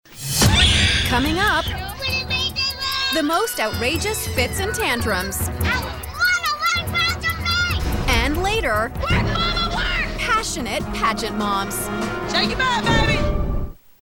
She has voiced everything from promos to cartoons to video games and narration.
Promotion - EN